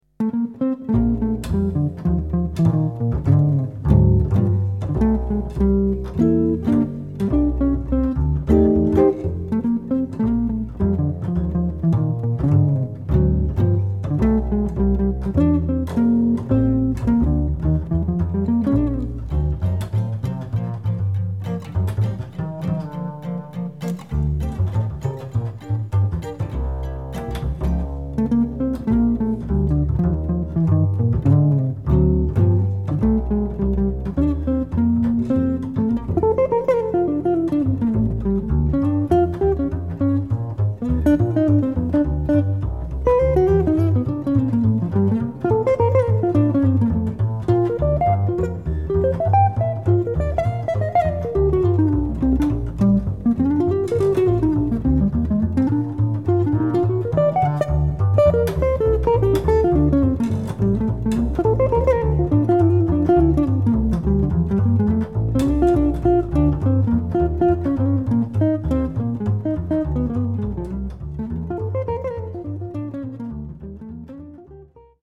Acoustic Mainstream Jazz auf 10 Saiten
Kontrabass
• dezent & unaufdringlich
• immer swingend, melodisch & harmonisch